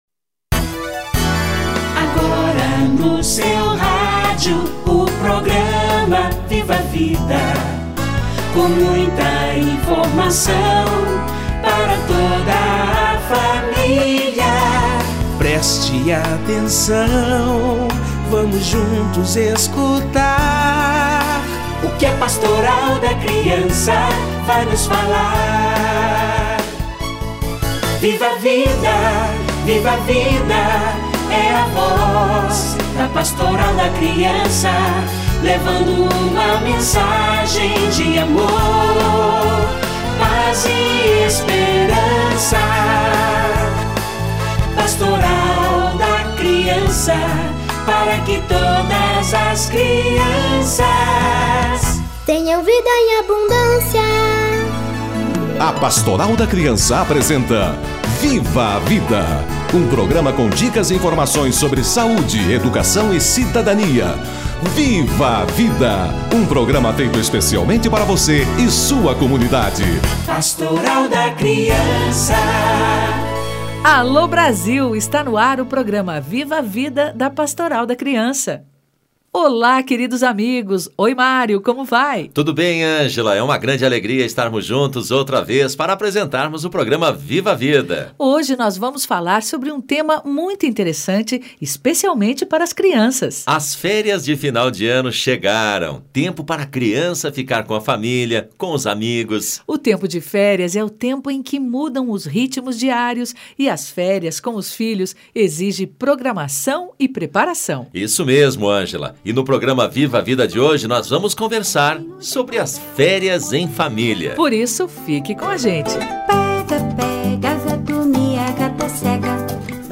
Férias em família - Entrevista